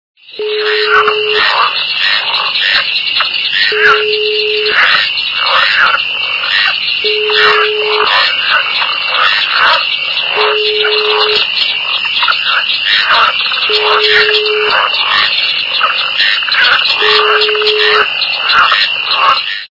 » Звуки » Природа животные » Лягушка - на болоте
При прослушивании Лягушка - на болоте качество понижено и присутствуют гудки.
Звук Лягушка - на болоте